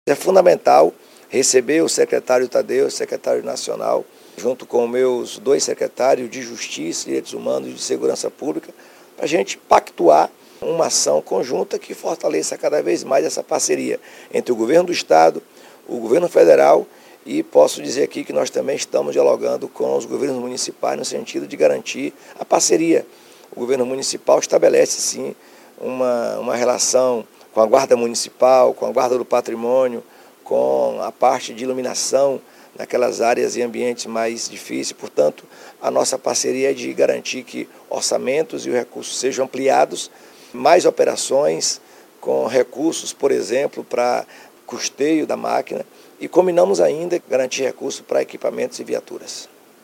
🎤 Jerônimo Rodrigues – Governador da Bahia